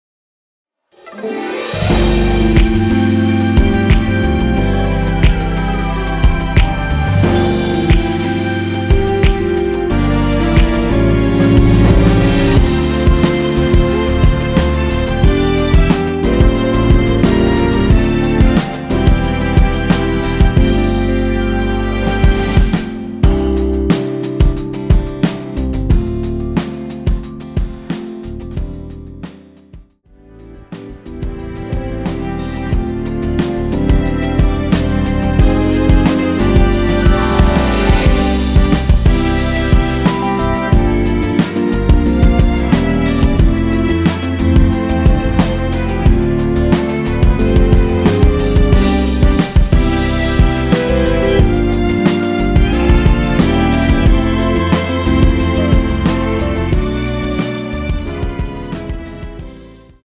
원키에서(-3)내린 (1절앞+후렴)으로 진행되는 MR입니다.
앞부분30초, 뒷부분30초씩 편집해서 올려 드리고 있습니다.
중간에 음이 끈어지고 다시 나오는 이유는